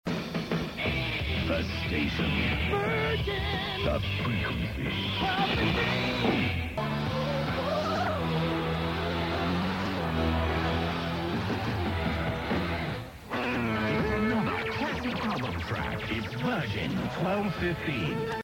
A couple of jingles